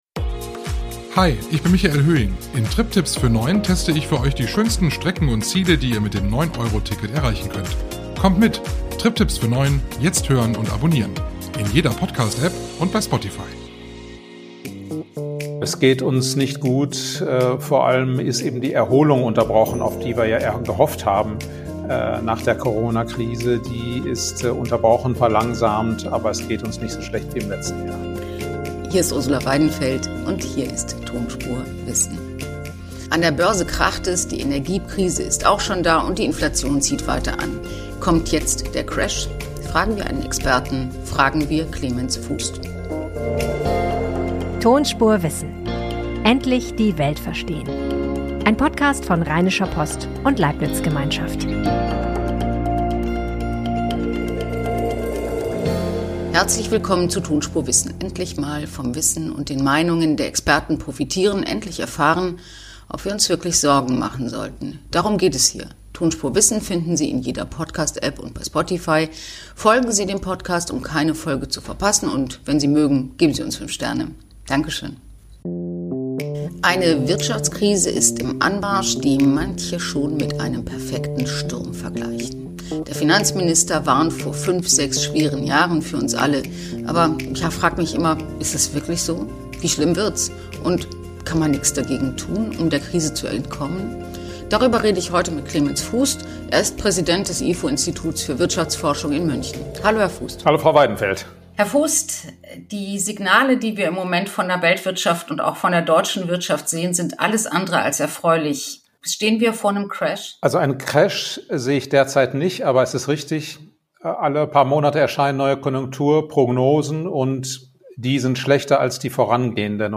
Die Prognosen in der Wirtschaft sehen düster aus. Kurse werden schwächer und die Hoffnung auf baldige Besserung schwindet. ifo-Chef Clemens Fuest erklärt im Gespräch mit Ursula Weidenfeld, warum Corona der Grund dafür ist, dass es uns wirtschaftlich nicht noch schlechter geht.